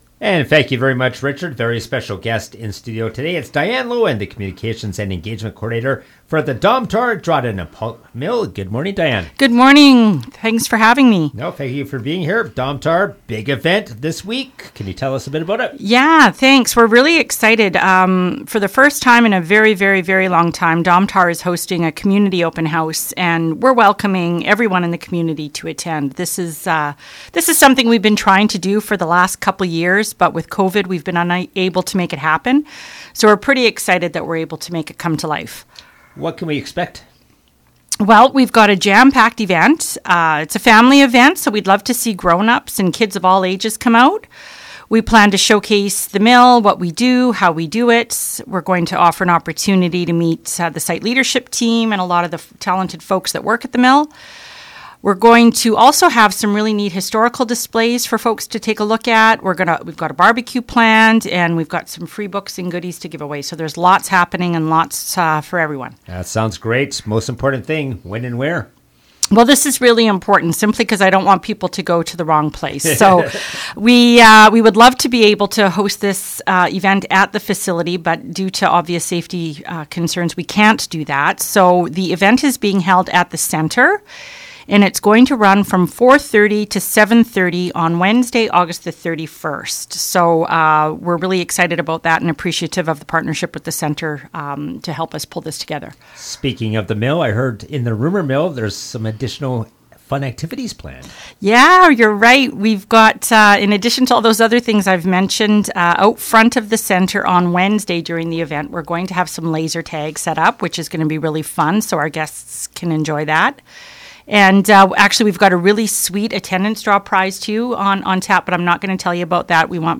a guest on the CKDR Morning Show Monday to outline what will be happening at the free event.